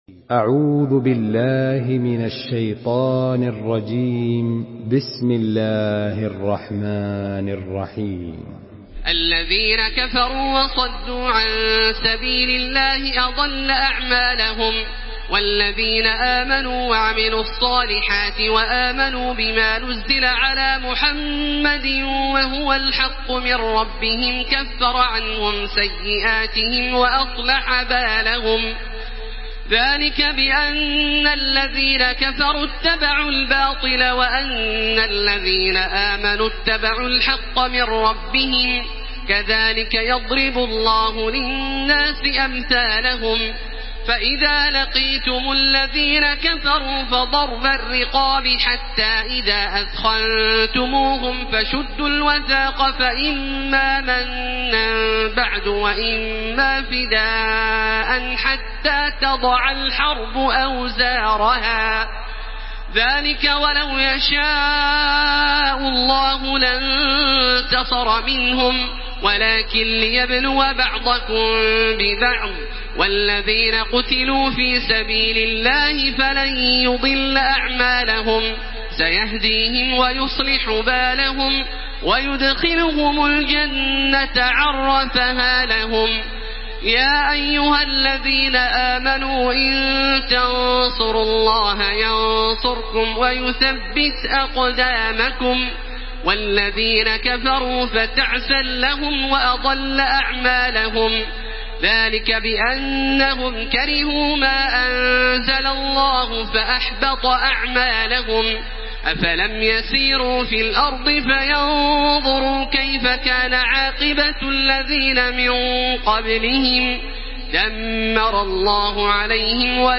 Makkah Taraweeh 1434
Murattal Hafs An Asim